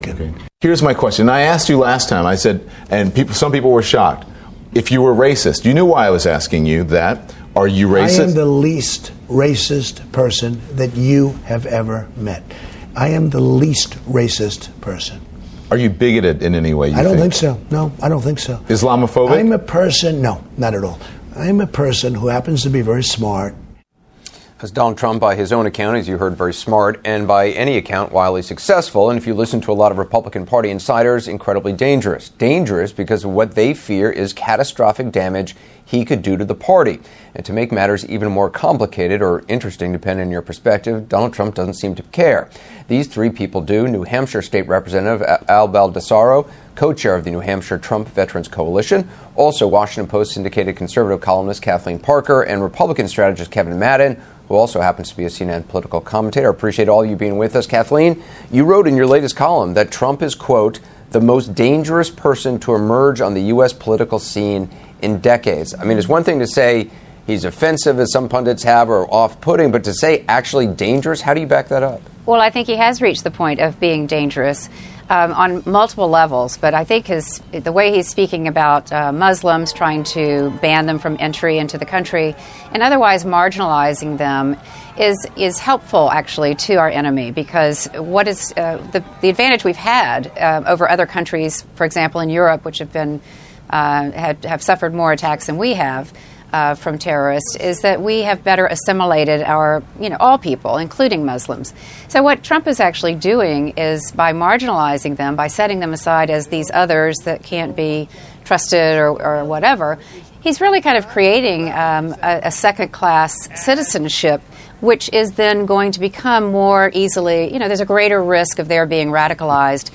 レベル３のリスニングでは、ある程度ネイティブスピーカーの話す英語に慣れていることを前提として、より実戦的なレッスンとして、CNNやCBS等の放送から3〜4分程の実際のニュースを抜粋したものを題材として穴埋め形式でディクテーションして頂きます。このサンプルは、今年の米国の大統領選挙に向けて立候補しているドナルド・トランプ氏の問題発言を議論する討論番組の抜粋になります。